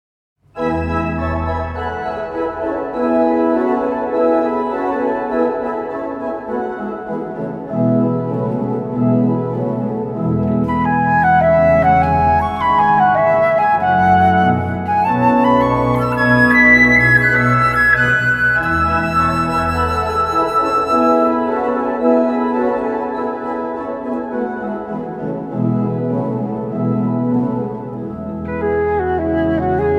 Rieger-Orgel in der Basilika der Abtei Marienstatt